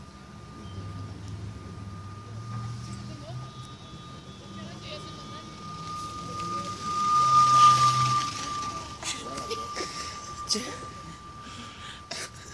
En utilisant l’analyse spectrale d’Audacity, on trouve que la fréquence avant le passage du vélo vaut $*f_{r1}*$ = 1216 Hz, et après le passage $*f_{r2}*$ = 1185 Hz.
son_doppler_velo.wav